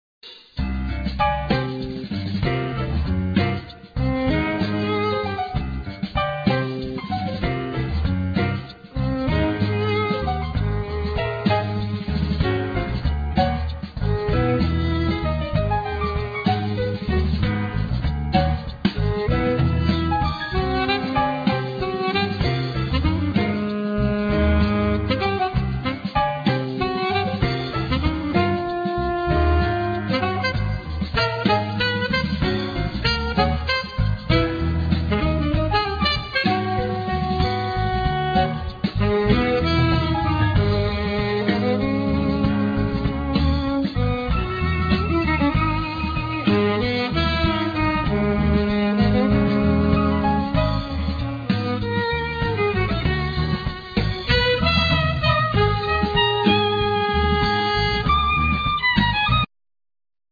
Alto & Tenor Saxophone
Viloin
Keyboards
Bass
Drums